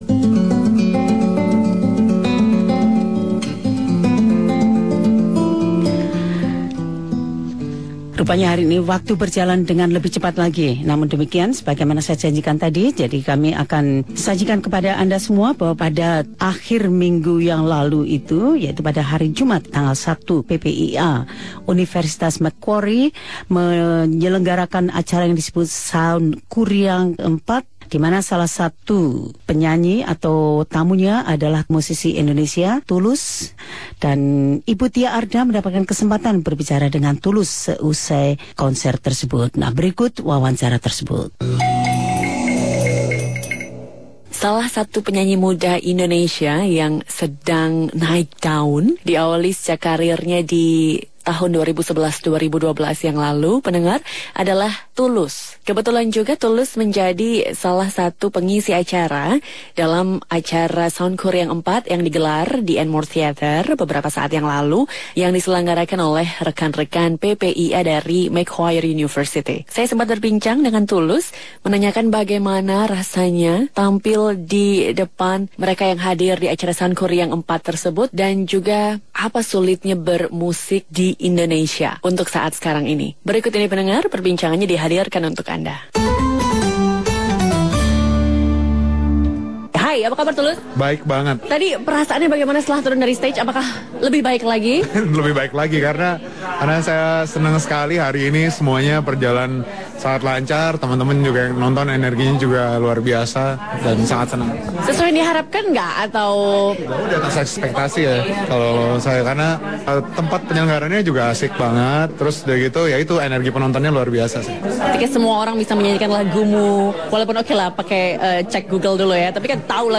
Song-writer and vocalist Tulus, answers these and related questions.